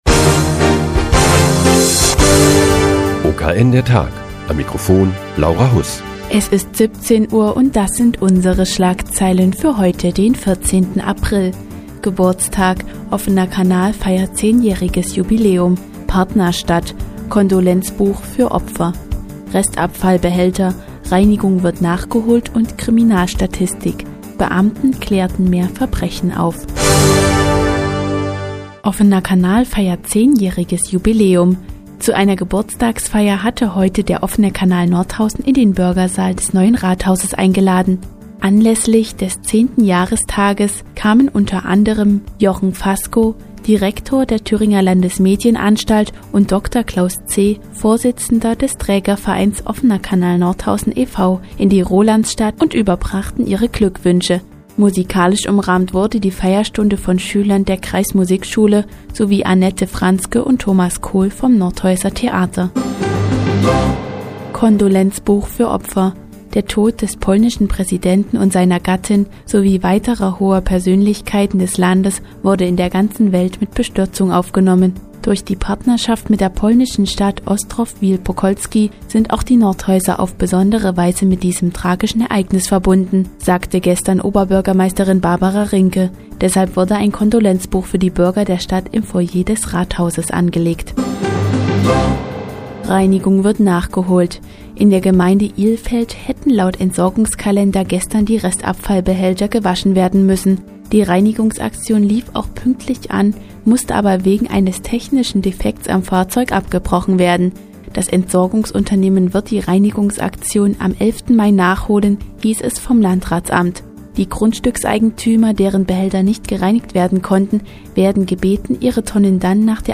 Die tägliche Nachrichtensendung des OKN ist nun auch in der nnz zu hören. Heute geht es um das 10-jährige Jubiläum des OKN und die Nordhäuser Kriminalstatistik des Jahres 2009.